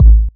808-Kicks34.wav